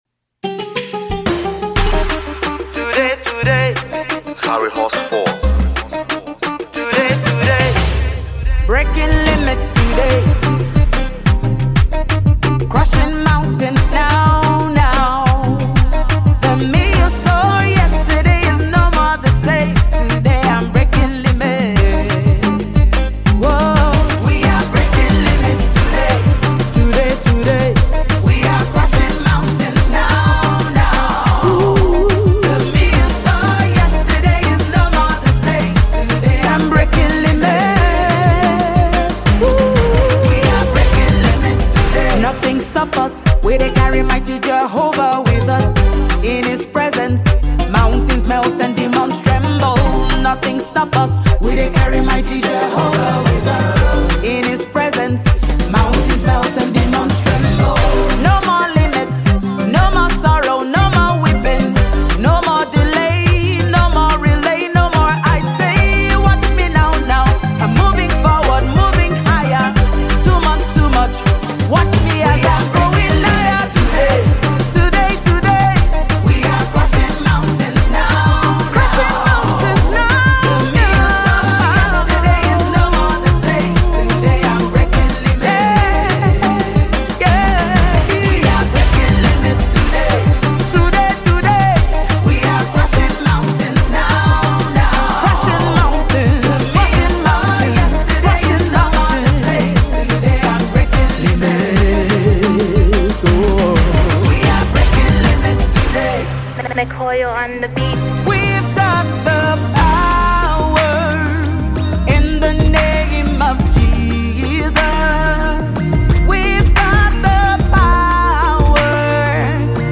a song of victory
and a prolific gospel music minister.